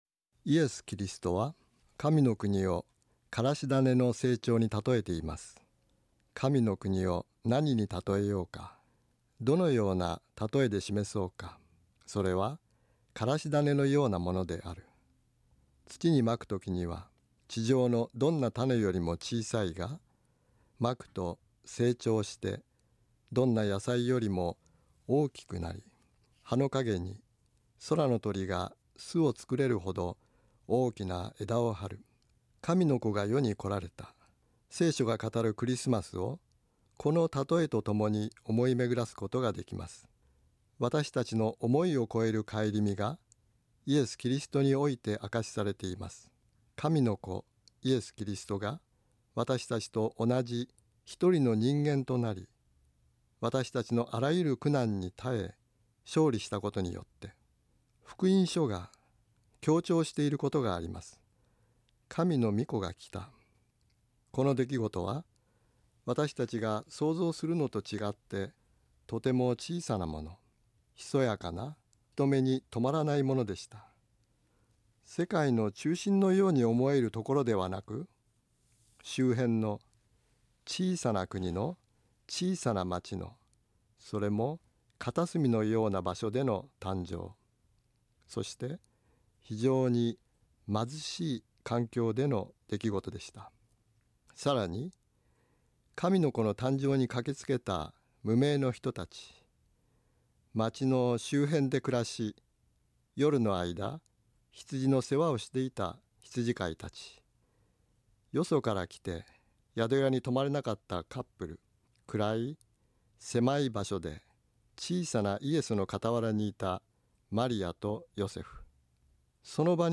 ラジオ番組「キリストへの時間」